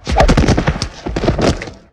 bodyslam.wav